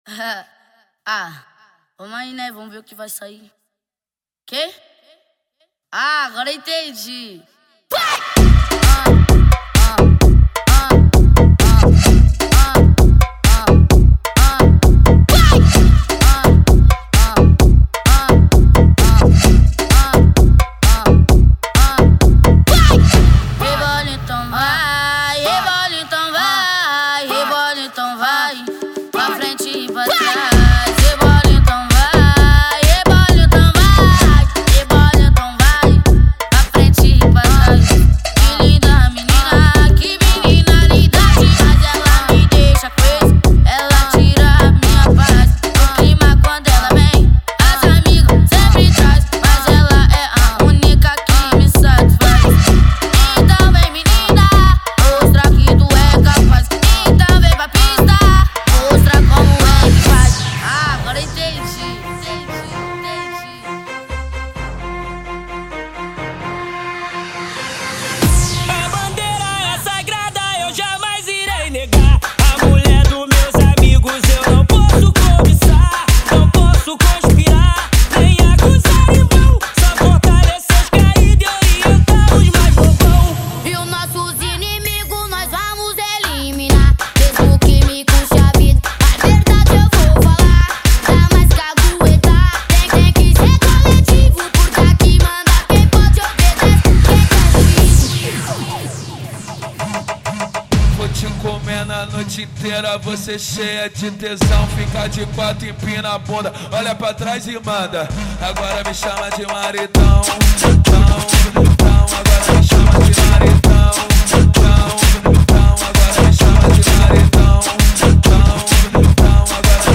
Eletro Funk